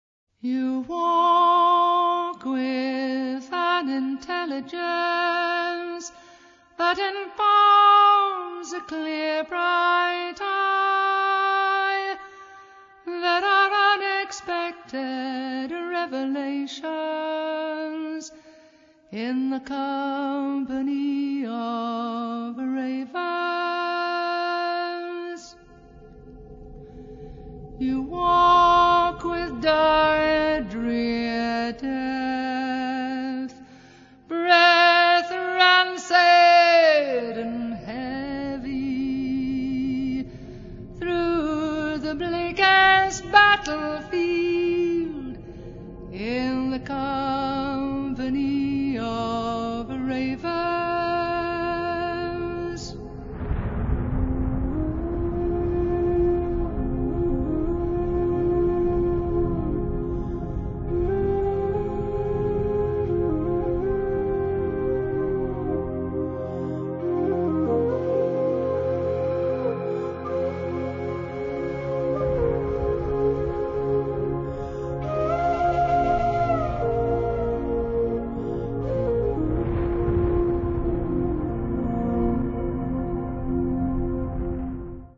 First part, 1:25 sec, mono, 22 Khz, file size: 333 Kb.